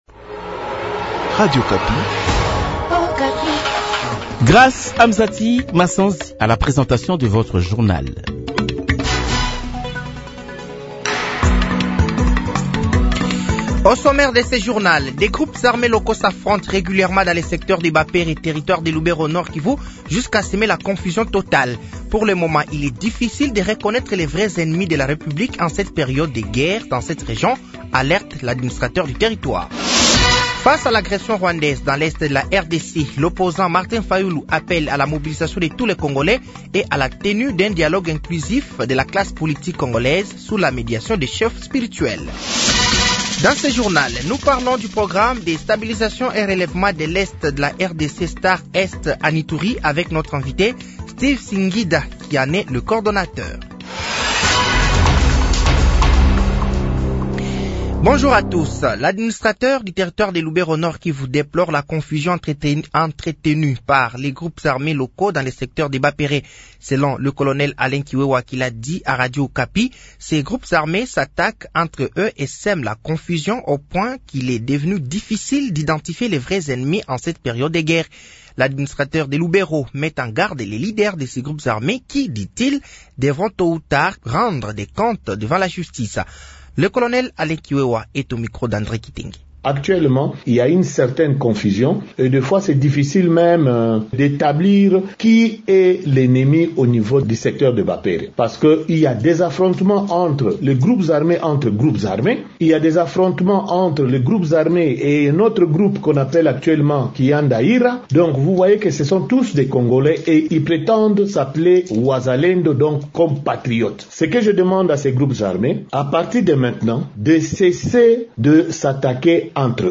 Journal de 15h
Journal français de 15h de ce dimanche 25 août 2024